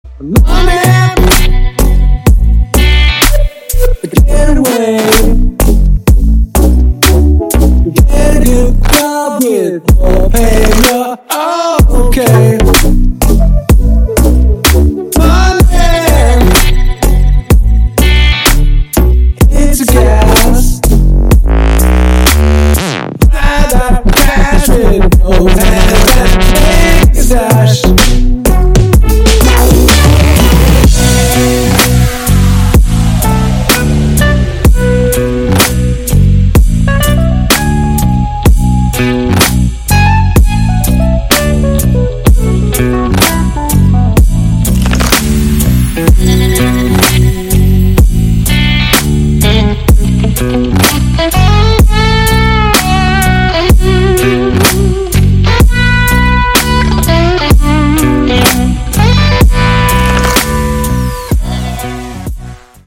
Рингтоны ремиксы
Рок рингтоны , Рингтоны техно , DubStep